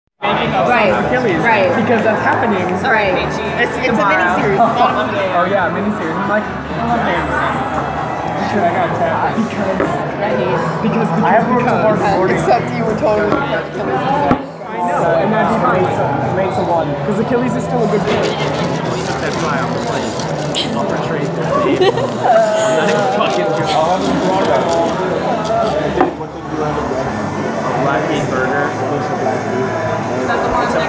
Field Recording 9
Location/Time: Buffalo Wild Wings, February 25th, 9:00 p.m.
Sounds Heard: people having a conversation, music playing, laughing, crinkling of a bag, cup being put down